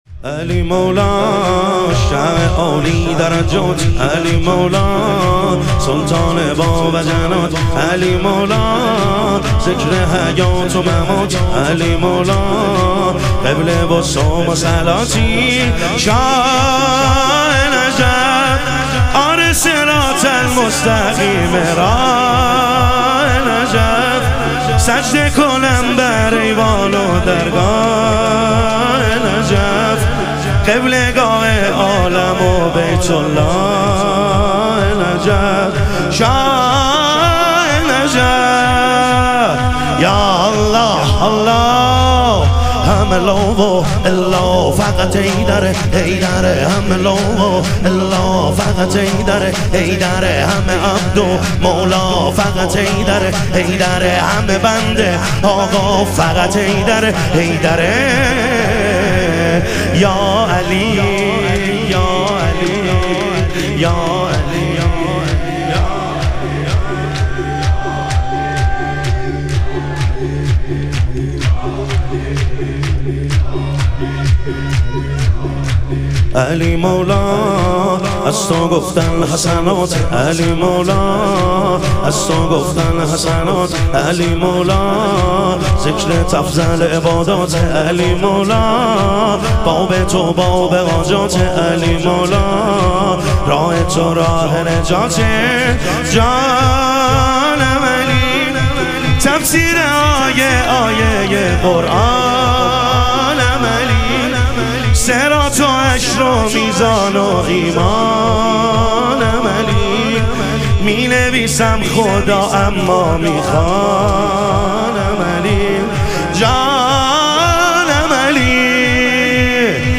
شهادت حضرت ام البنین علیها سلام - شور